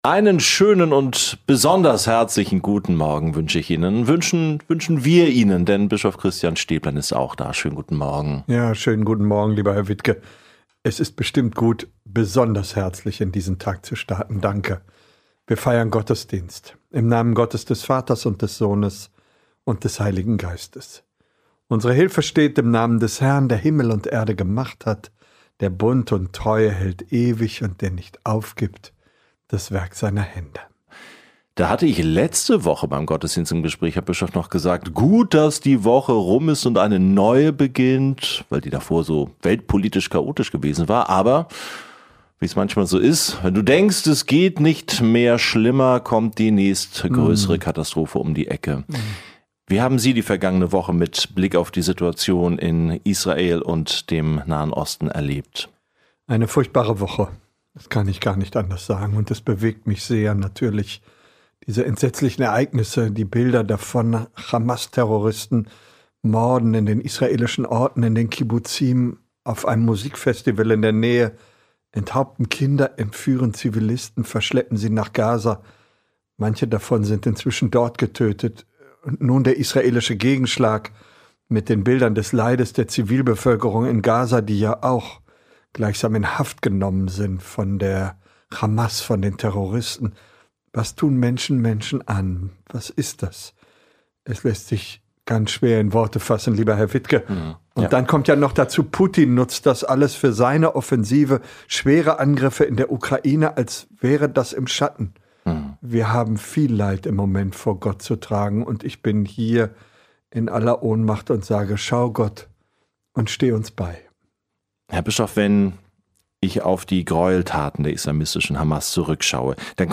Die schrecklichen Ereignisse im Nahen Osten. Wie umgehen mit Hass und Hetze auch hier in Deutschland? Woher kommt mir Hoffnung? Ein Gottesdienst, der nach spirituellen Antworten sucht.